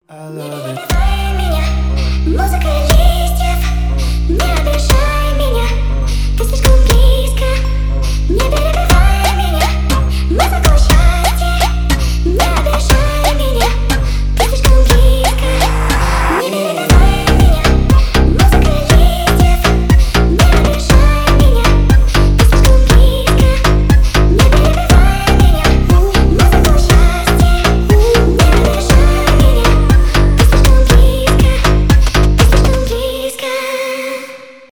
ремиксы
trap , jersey club
поп